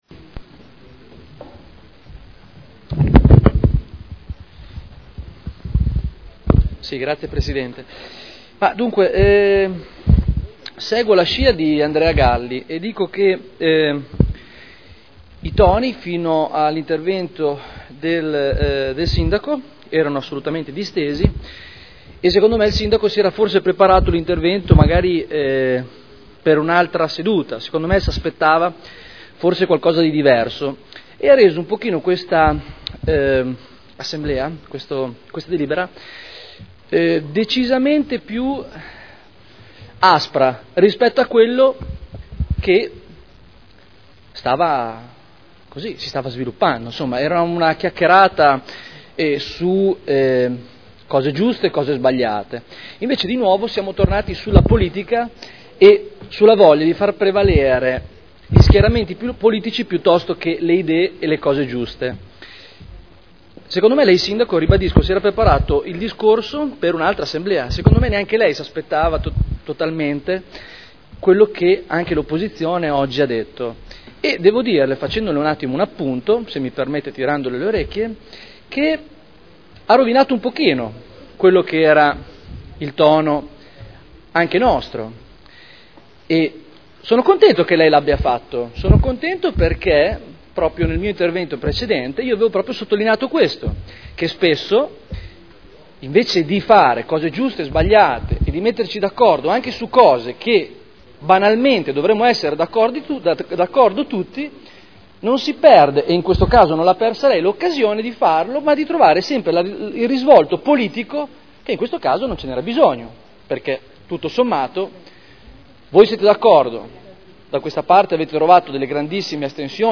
Seduta del 20/02/2012.
Dichiarazione di voto. Nulla osta al rilascio o presentazione di titolo abilitativo edilizio in deroga agli strumenti urbanistici (art. 15 L.R. 31/2002 e art. 31.23 RUE) – Richiesta presentata dalla Comunità Islamica di Modena (Commissione consiliare del 7 febbraio 2012)